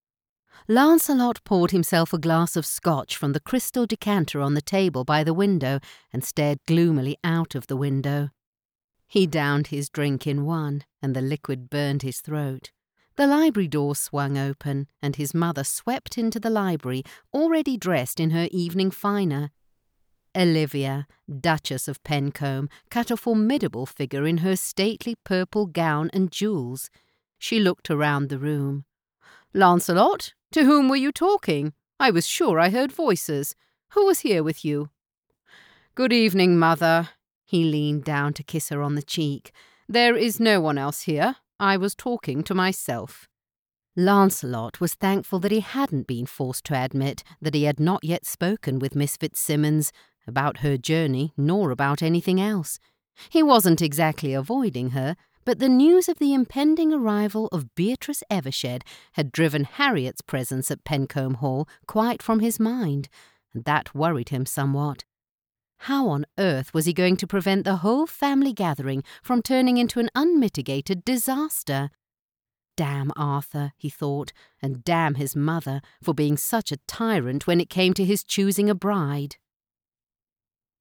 Mi acento inglés neutro funciona bien en los mercados internacionales.
Mi voz es natural y amigable, pero resonante y autoritaria. A mis clientes también les encanta mi voz seductora.
Micrófono Audio Technica AT2020